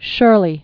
(shûrlē)